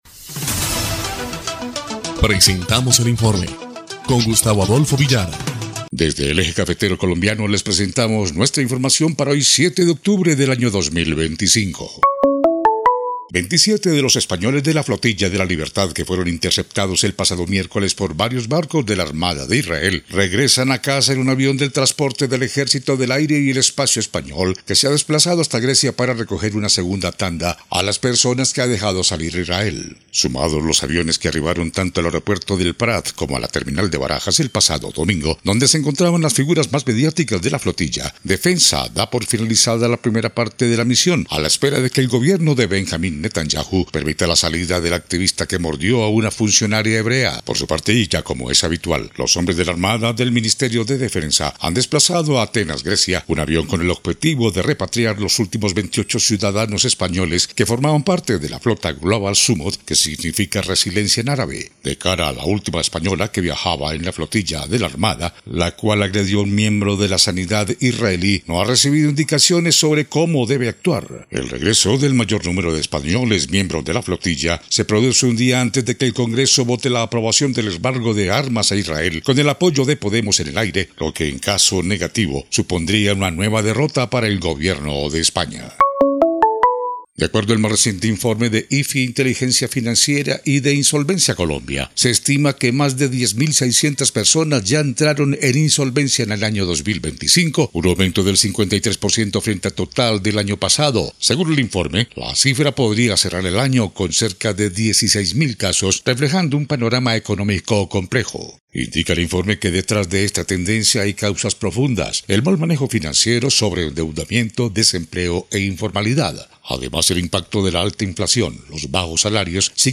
EL INFORME 2° Clip de Noticias del 7 de octubre de 2025